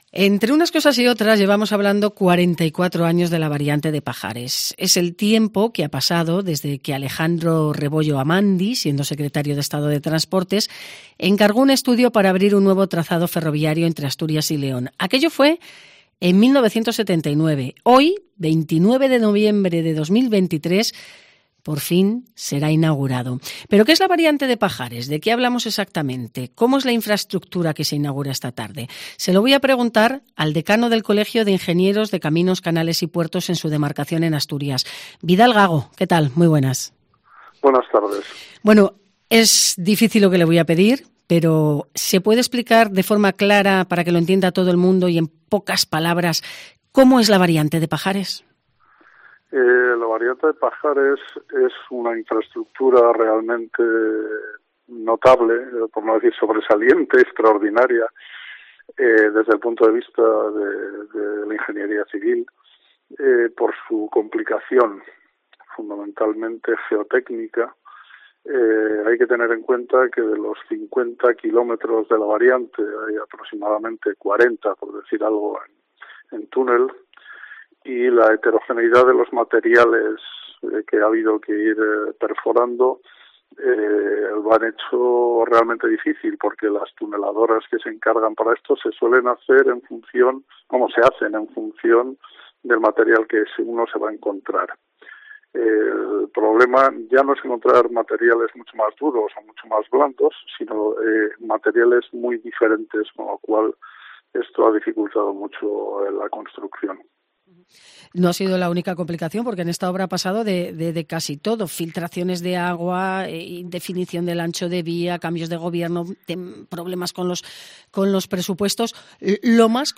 En una entrevista en COPE ha explicado los motivos.